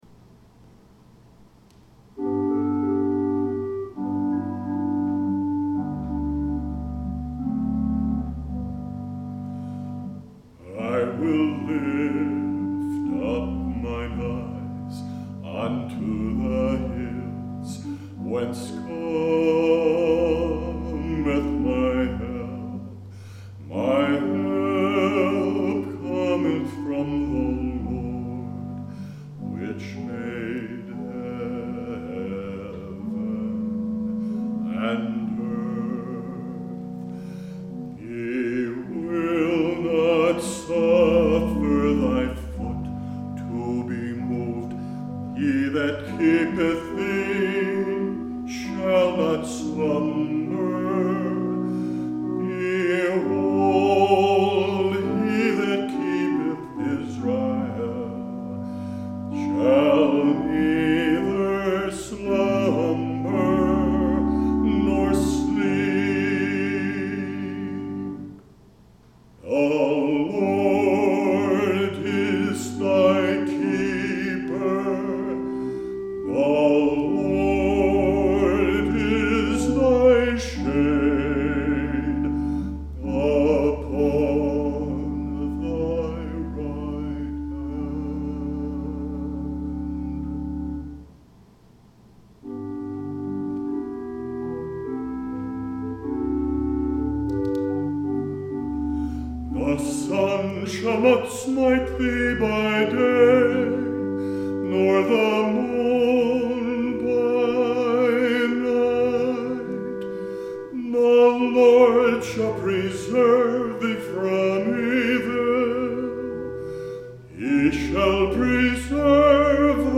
Latest Solos
I Will Lift Up Mine Eyes - Young 2/23/25 Grass Valley